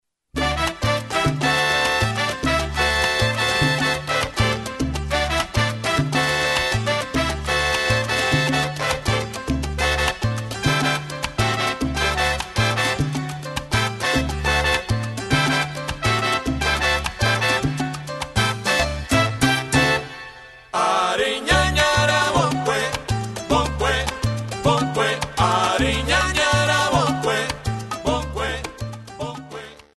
Category: Salsa
Style: Mambo
Solos: coro (background vocals)